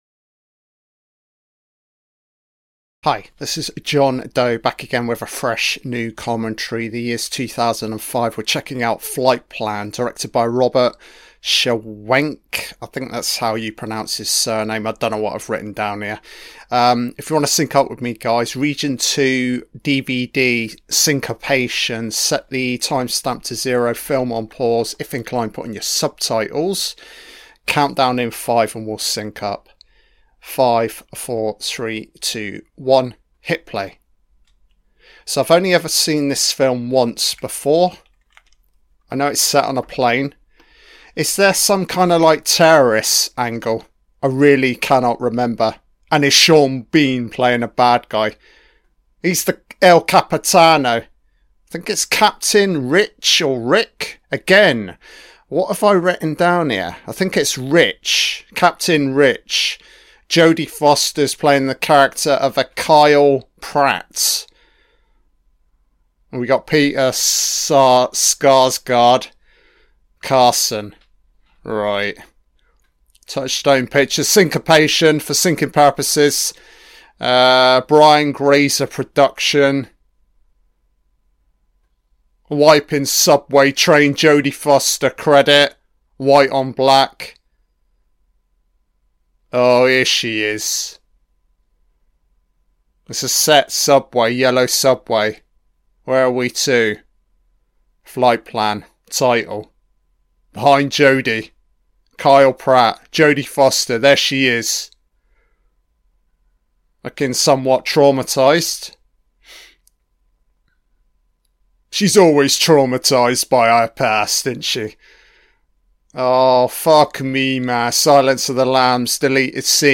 An Audio-Only Commentary on the 2005 film FLIGHTPLAN